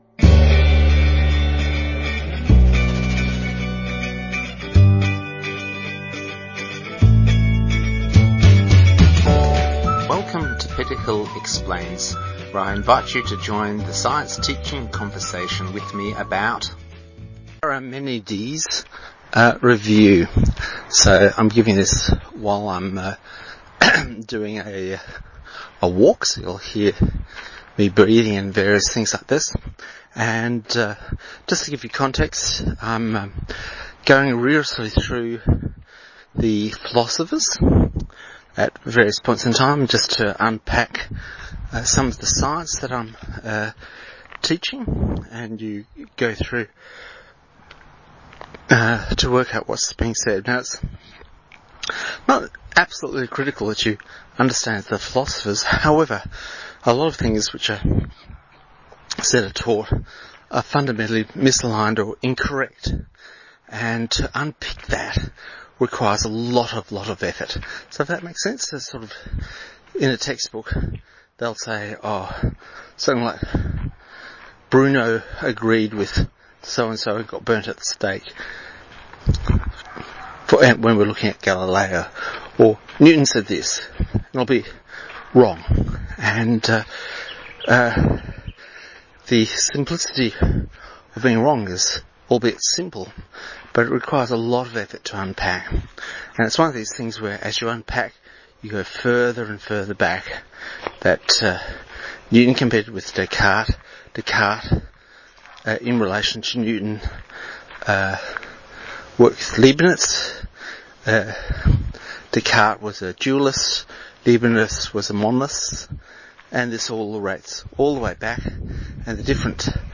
Just a quick podcast while walking looking at the confusing idea of Negative Existentialism that is solved by modern set theory. Also a look at Monism Dualism, Atomist and a reflection about the prevailing field theories in Physics.